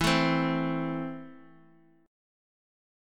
Esus4#5 chord